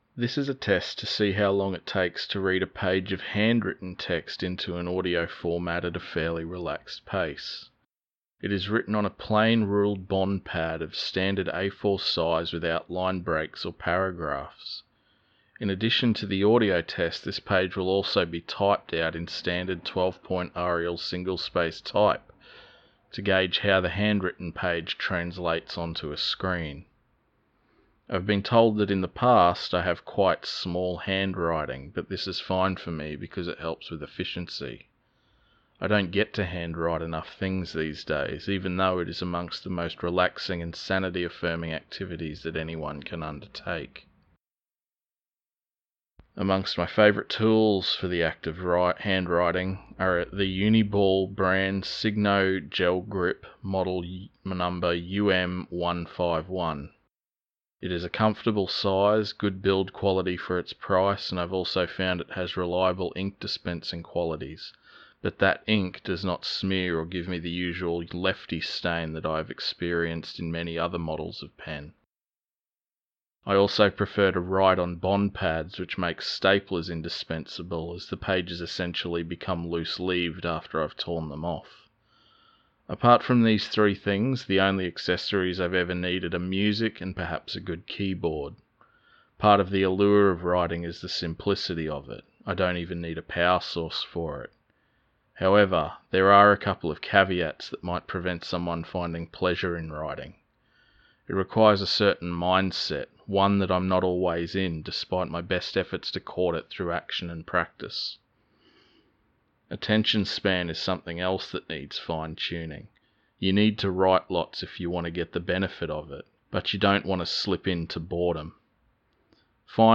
Another reading test, this time using an A4 page of handwritten text. My skills are a bit rusty - I'm so used to reading silently that my mouth can't keep up.